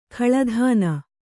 ♪ khaḷa dhāna